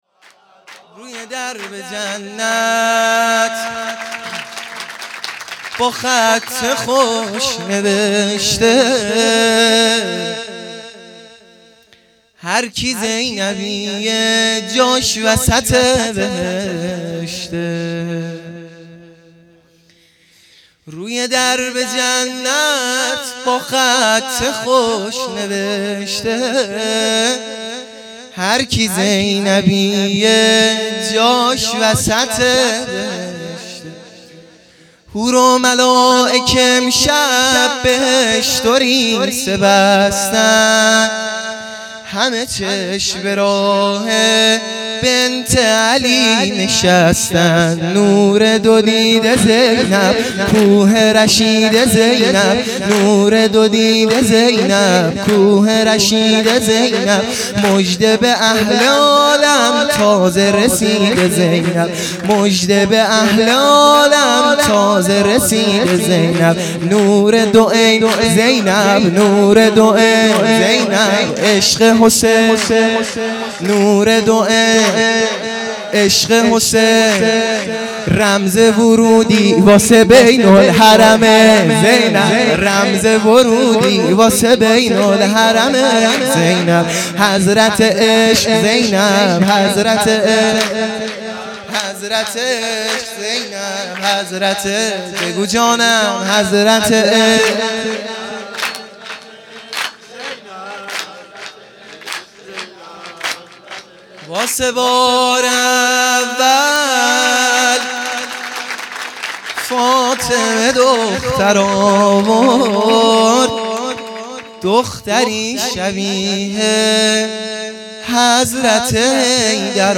میلاد حضرت زینب سلام الله